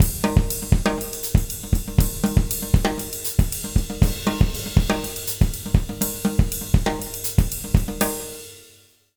120SALSA02-L.wav